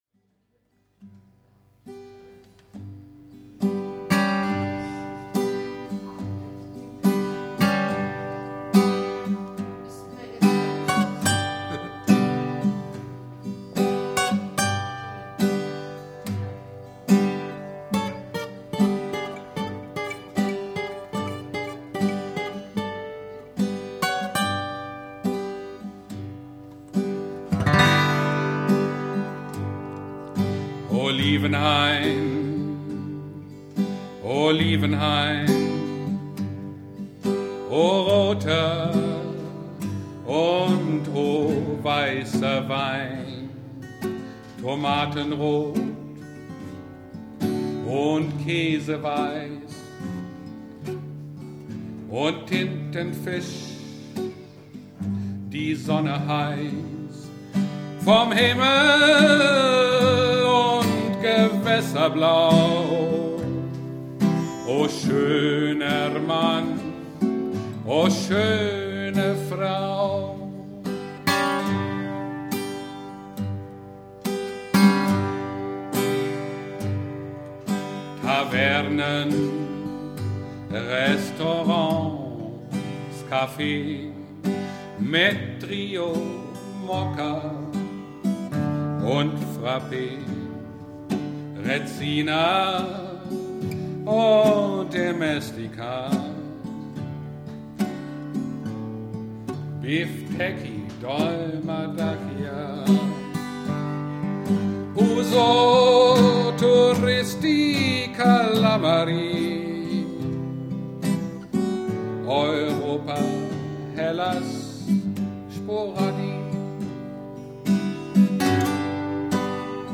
Live im Kulturzentrum Wülperode